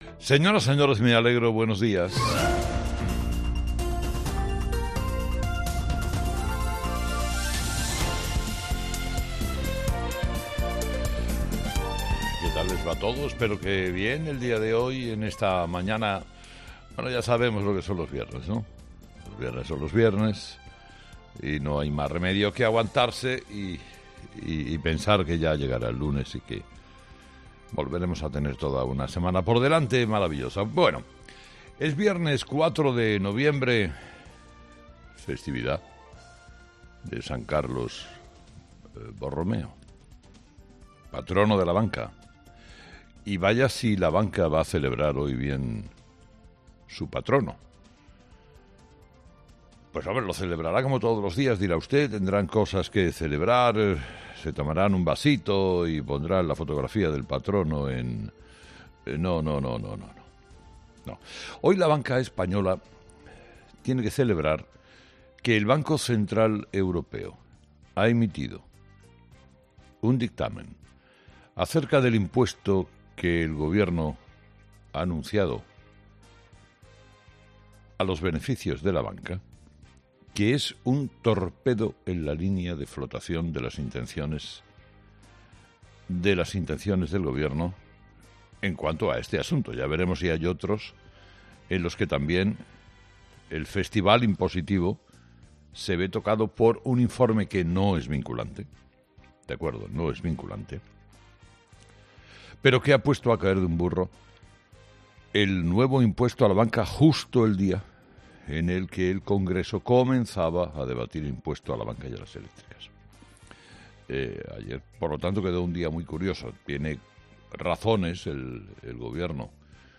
Carlos Herrera repasa los principales titulares que marcarán la actualidad de este viernes 04 de noviembre en nuestro país
Carlos Herrera, director y presentador de 'Herrera en COPE', ha comenzado el programa de este viernes analizando las principales claves de la jornada, que pasan, entre otros asuntos, por la directriz que ha hecho el Banco Central Europeo a España tras conocer la intención de establecer un nuevo impuesto a la banca.